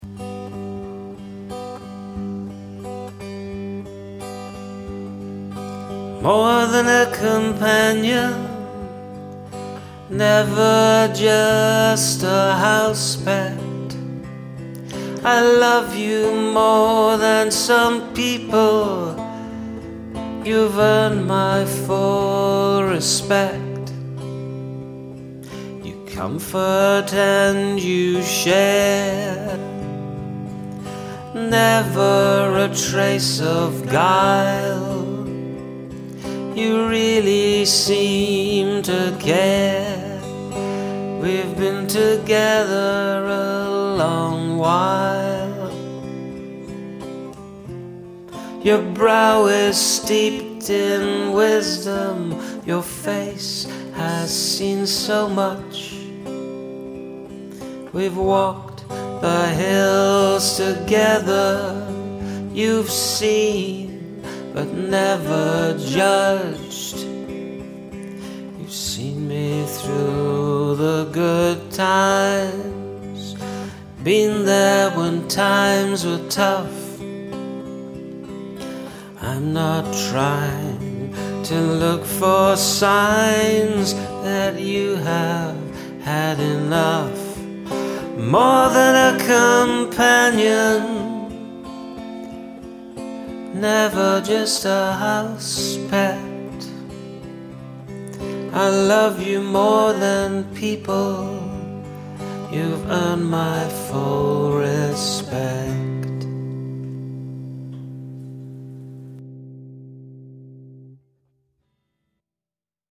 Love the elegant harmonies. Such a nice folk song vibe.
So soft and gentle, lovely simple picking.
The melody and words are sung so beautifully.
Lovely harmonies.